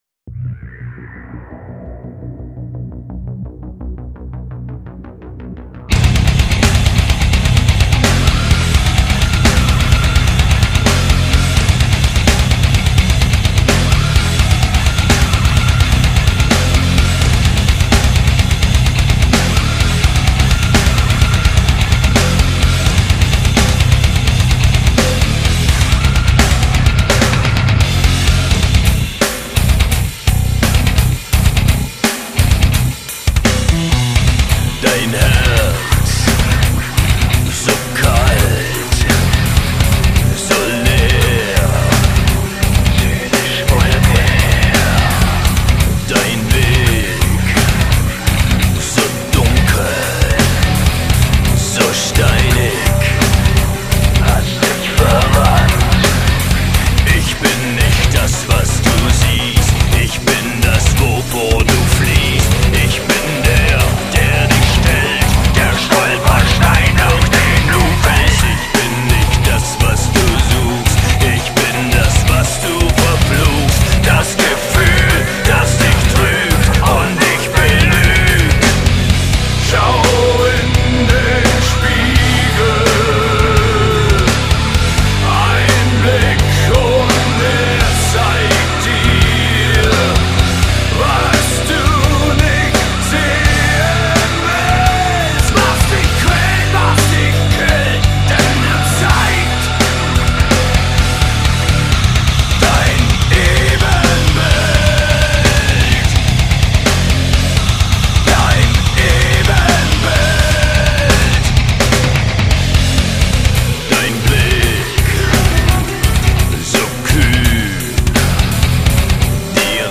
Жанр: Industrial, Alternative